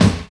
45 KICK 7.wav